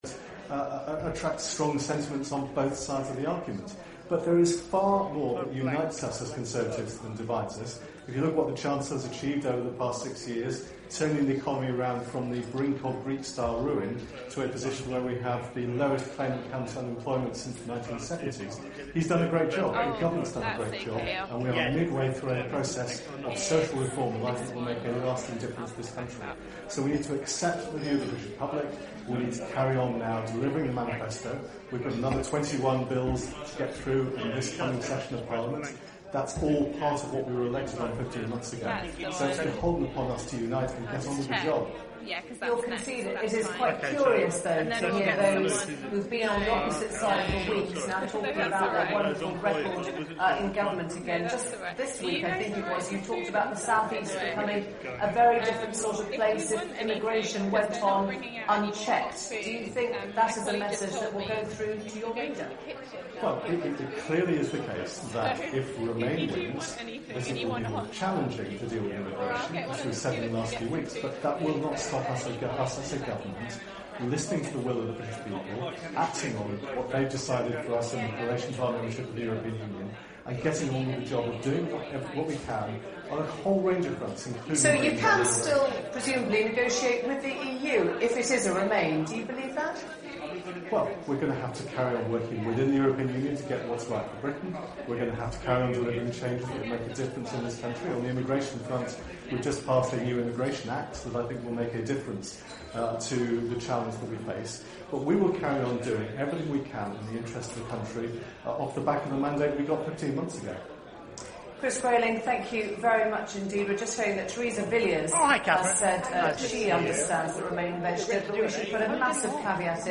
The LSE European Institute in partnership with The UK in a Changing Europe organised the LSE Referendum Night 2016, an evening of panel debates.
The evening included commentaries and debates between LSE academics and external experts as the first results were reported from across the nation.